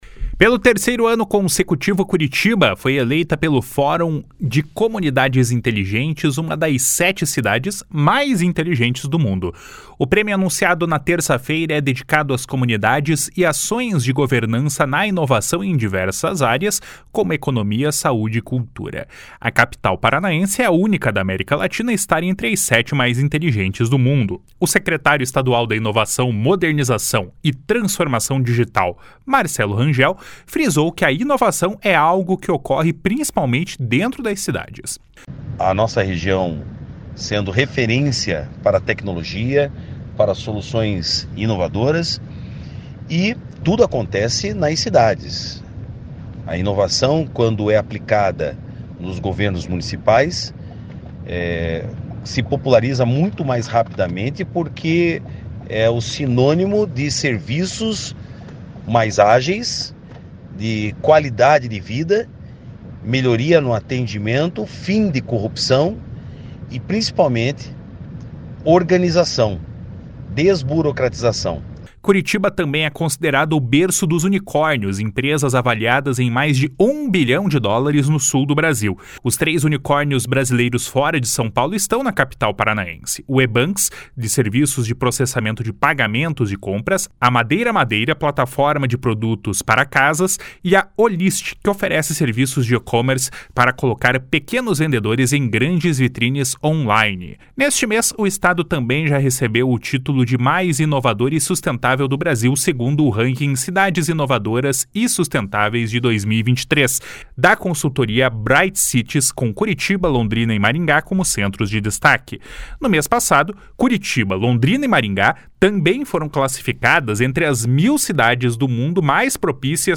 // SONORA MARCELO RANGEL //